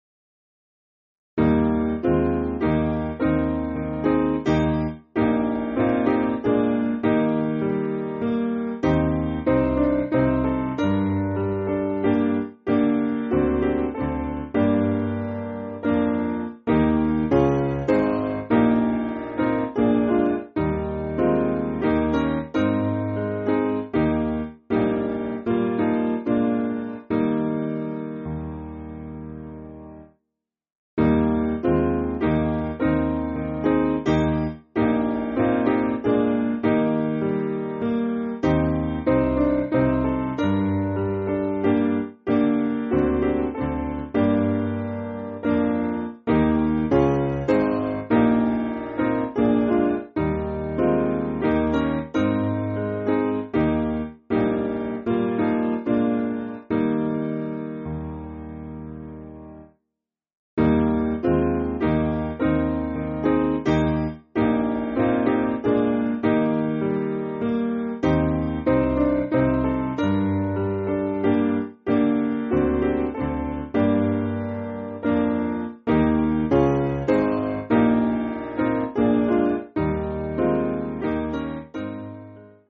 Simple Piano
(CM)   5/Eb